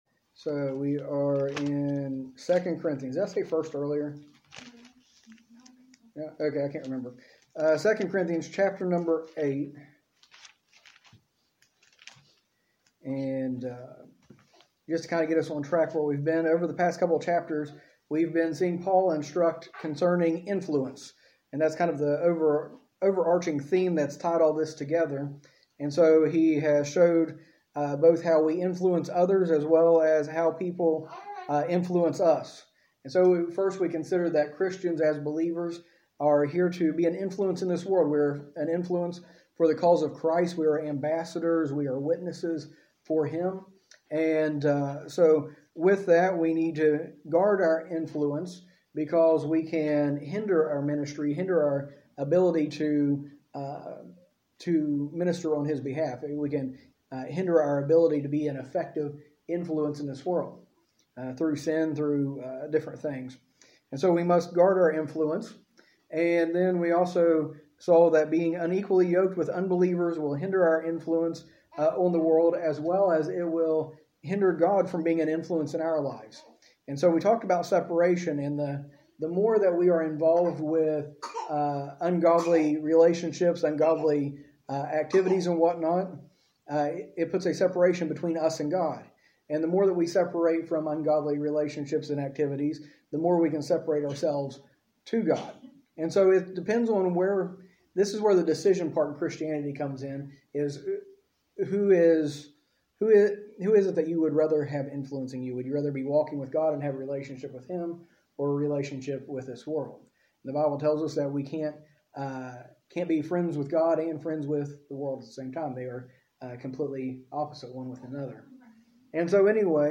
A message from the series "2 Corinthians."